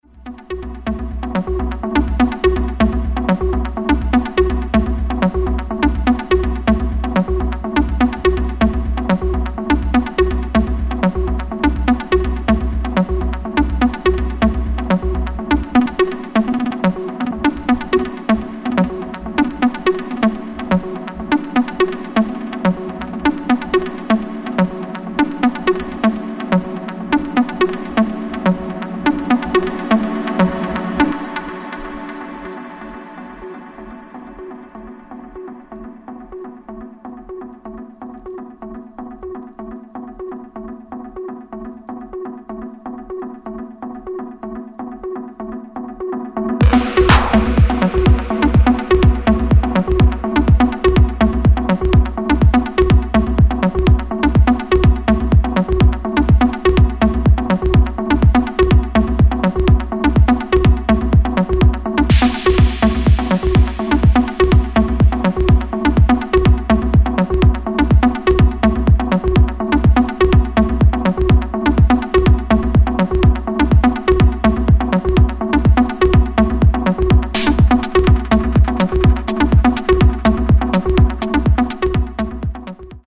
This is deep, throbbing & minimal just the way you like it.